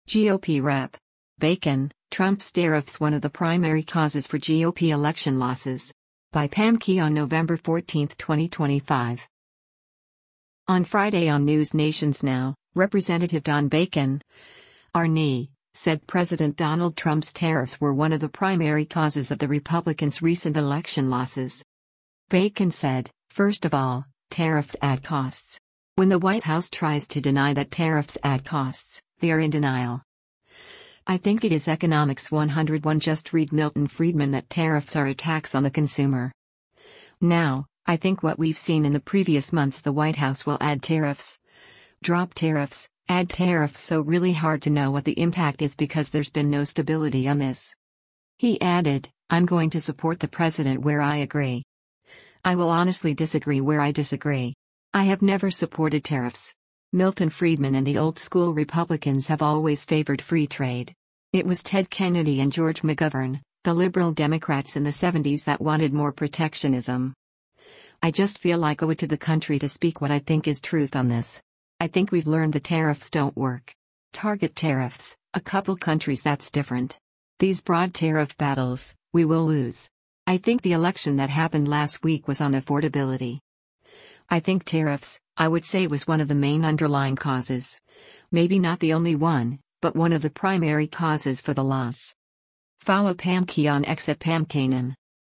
On Friday on NewsNation’s “Now,” Rep. Don Bacon (R-NE) said President Donald Trump’s tariffs were one of the “primary causes” of the Republicans’ recent election losses.